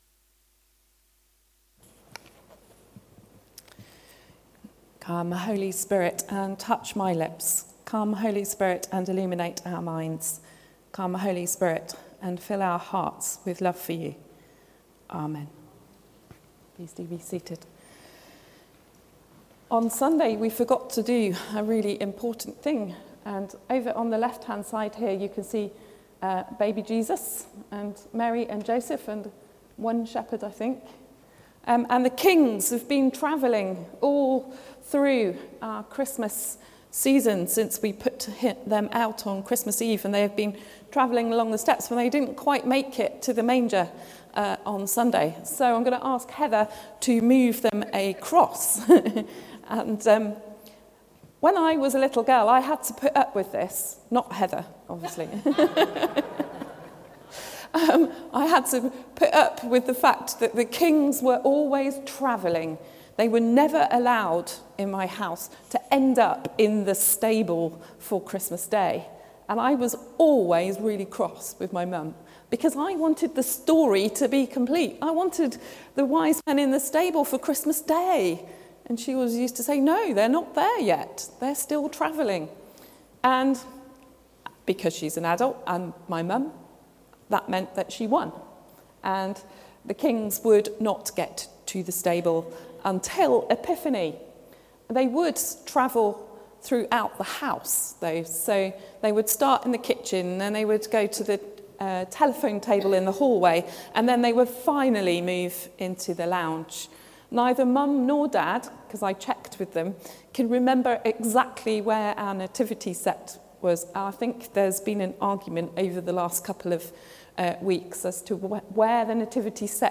Sermon: I Have Loved You Deeply | St Paul + St Stephen Gloucester